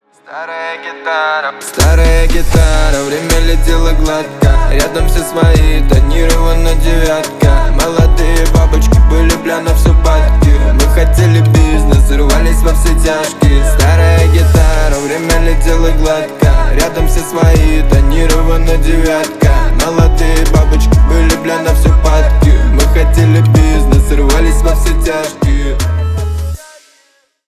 Поп Музыка
грустные
спокойные